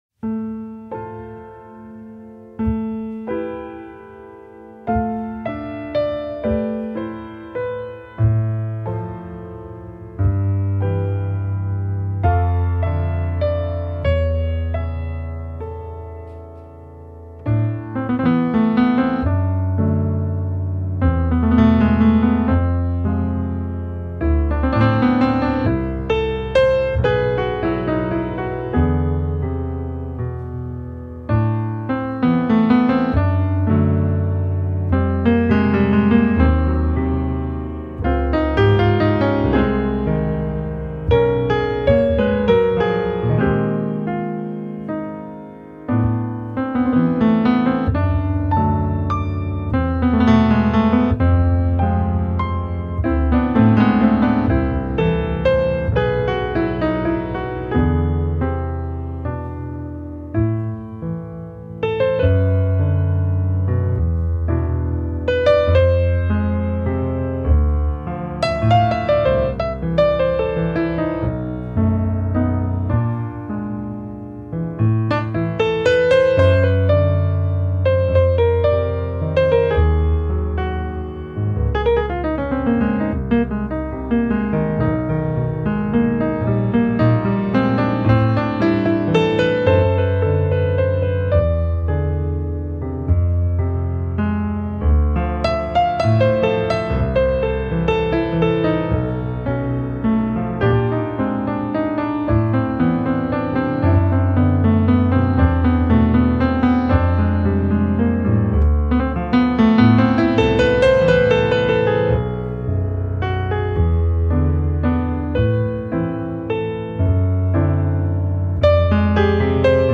C’est festif mais pas trop.
dans l’auditorium de la Tour des arts des Herbiers